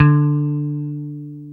Index of /90_sSampleCDs/Roland - Rhythm Section/GTR_Dan Electro/GTR_Dan-O Guitar